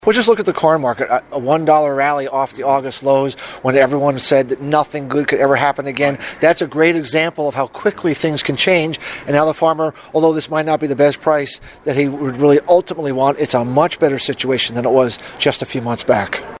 The 20th annual Kentucky Soybean Promotion Day at Murray State University Tuesday provided a reality check, a word of optimism for the future of agriculture, and a look at potential weather influences.